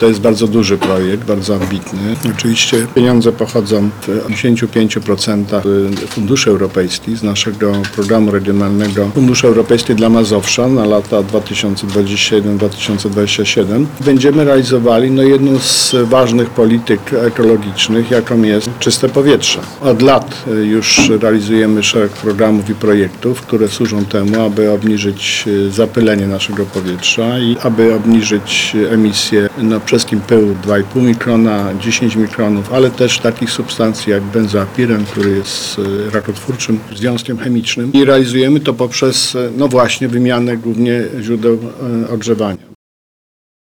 Mówi Marszałek Województwa Mazowieckiego Adam Struzik.